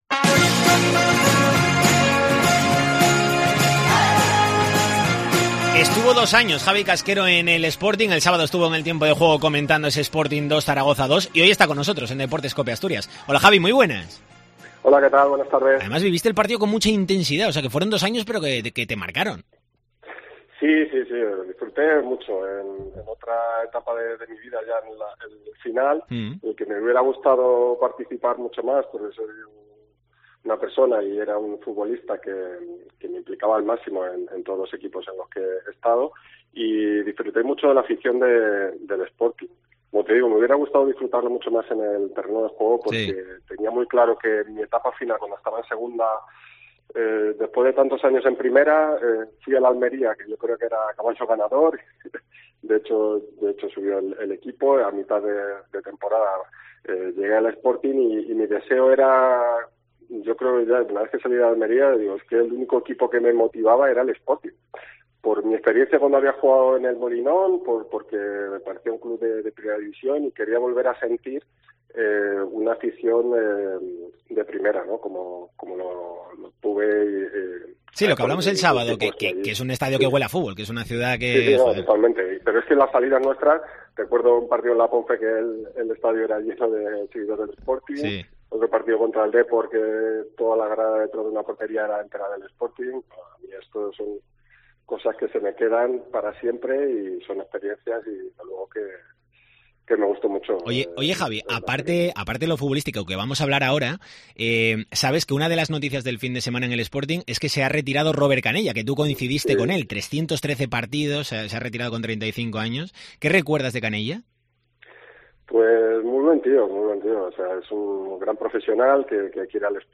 Ha estado en Deportes COPE Asturias hablando de su recuerdo del Sporting, de la retirada de su ex compañero Canella y del actual estado de forma del equipo de Miguel Ángel Ramírez después del empate ante el Zaragoza.